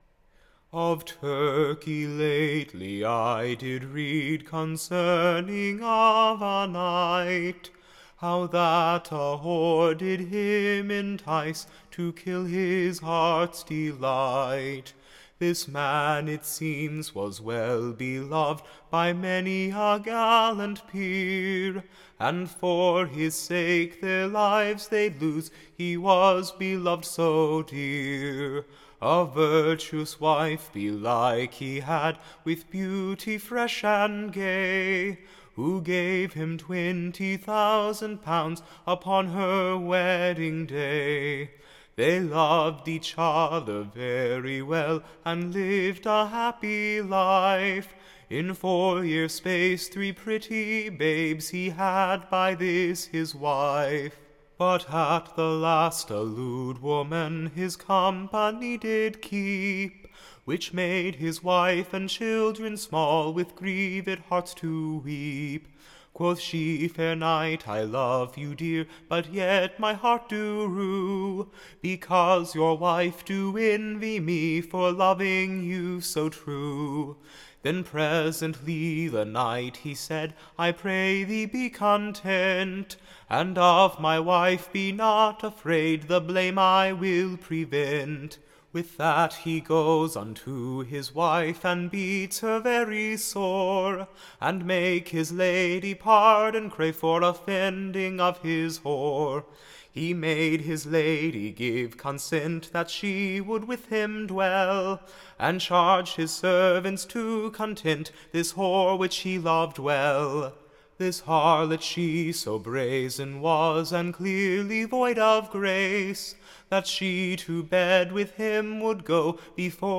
Recording Information Ballad Title The Patient Wife betrayed; / OR, / The Lady Elizabeths Tragedy.
Tune Imprint To the Tune of, Chevy Chase, or The Lady Izabells Tragedy.